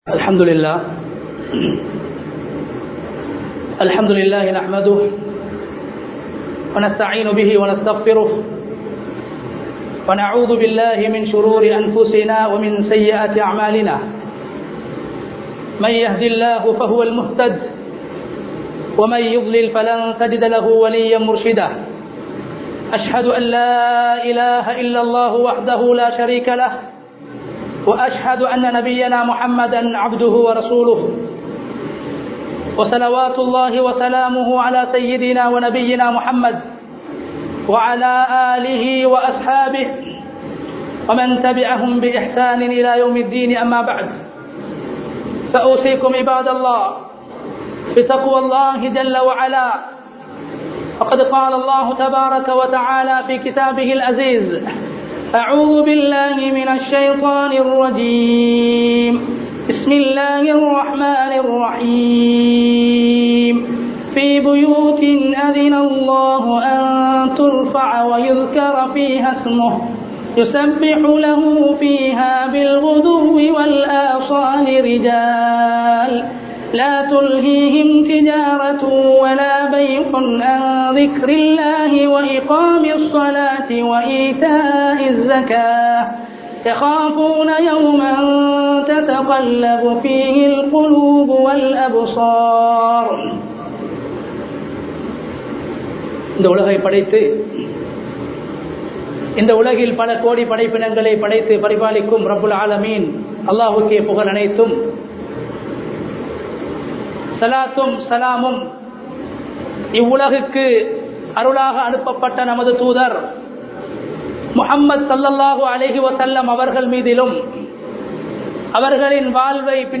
மஸ்ஜிதின் மகிமை ( Glory of the Masjid) | Audio Bayans | All Ceylon Muslim Youth Community | Addalaichenai